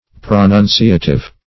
Search Result for " pronunciative" : The Collaborative International Dictionary of English v.0.48: Pronunciative \Pro*nun"ci*a*tive\, a. [L. pronunciativus.]